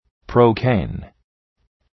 Προφορά
{‘prəʋkeın}